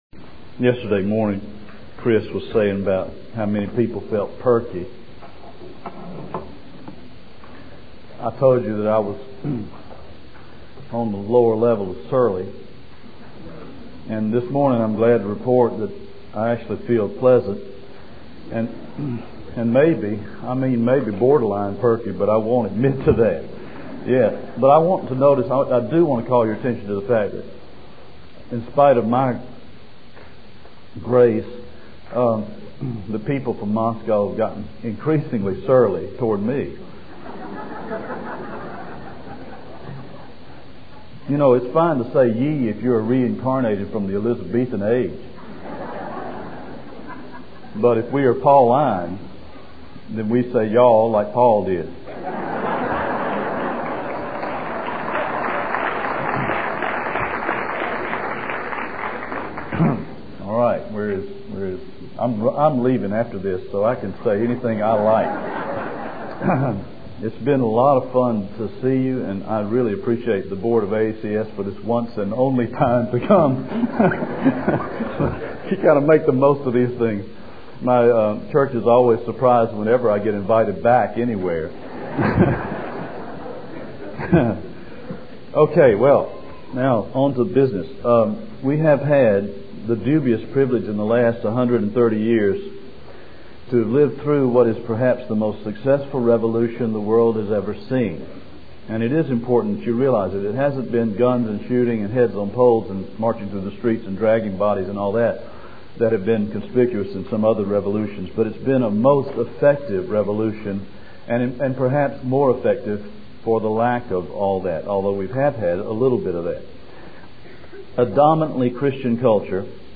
2000 Workshop Talk | 0:54:22 | All Grade Levels, Culture & Faith